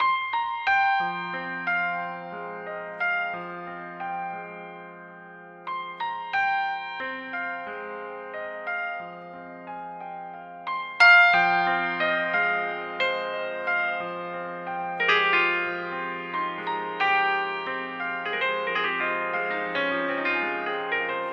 知识分子钢琴
标签： 180 bpm Trap Loops Piano Loops 3.59 MB wav Key : F Cubase
声道立体声